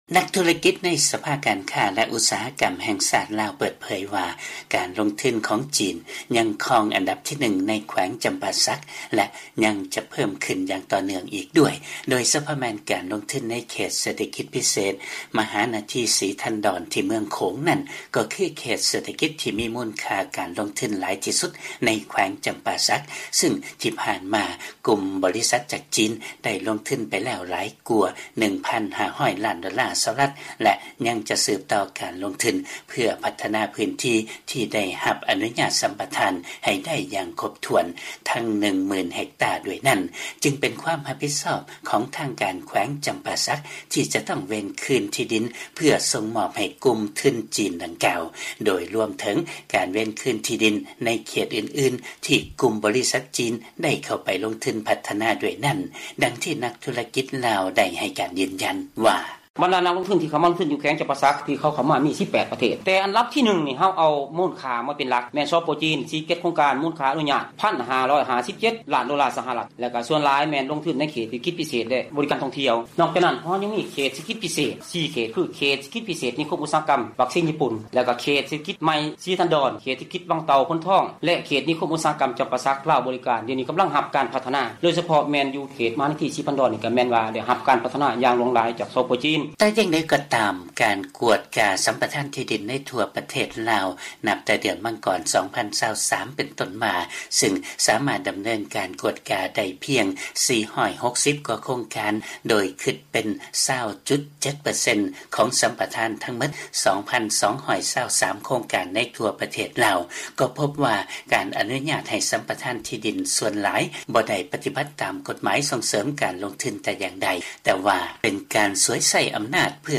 by ສຽງອາເມຣິກາ ວີໂອເອລາວ